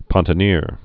(pŏntə-nîr)